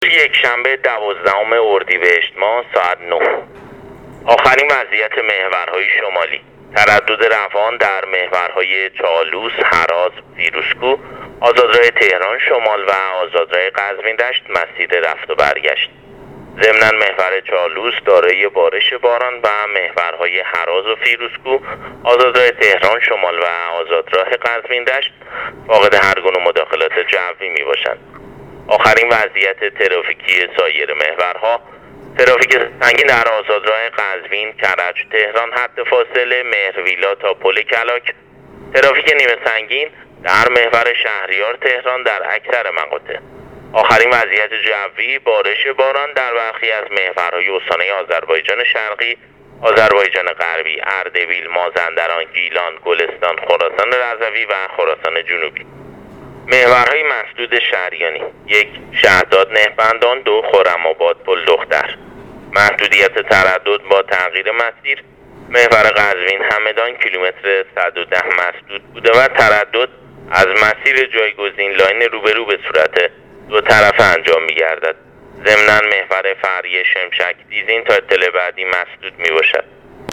گزارش رادیو اینترنتی از آخرین وضعیت ترافیکی جاده‌ها تا ساعت ۹ دوازدهم اردیبهشت